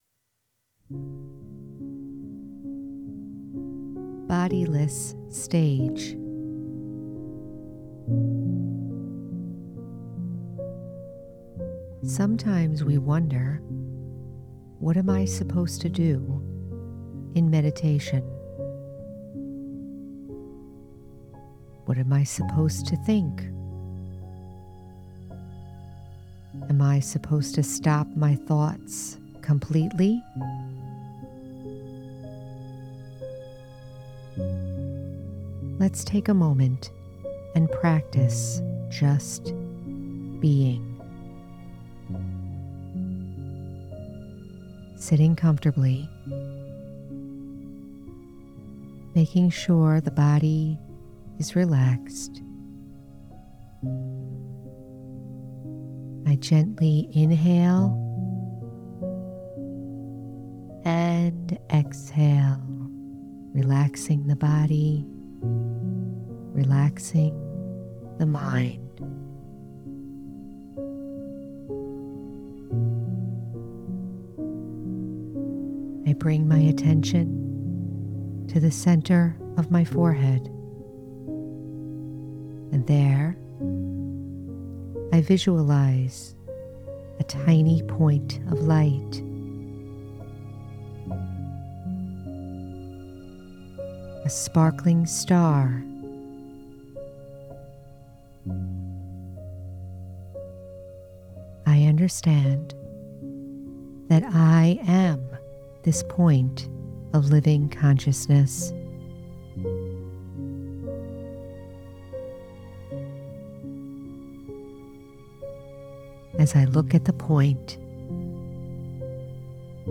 Bodiless Stage- Guided Meditation- The Spiritual American- Episode 163